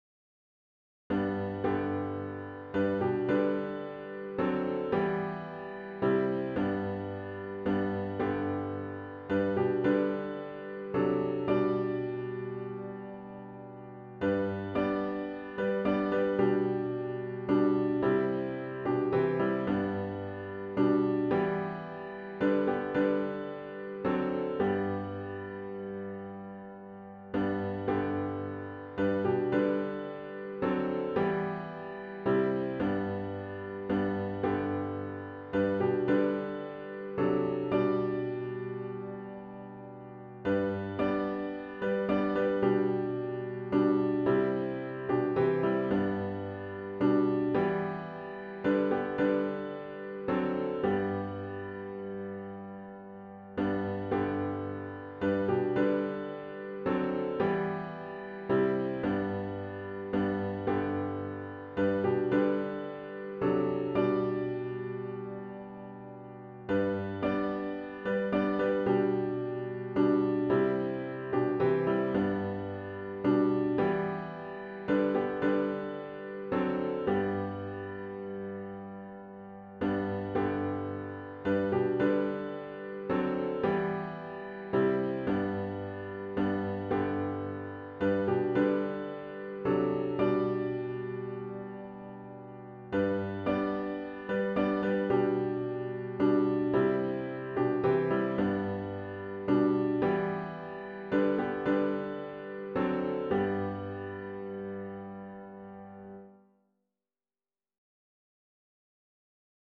*HYMN “Amazing Grace, How Sweet the Sound” GtG 649 (Verses 1-4)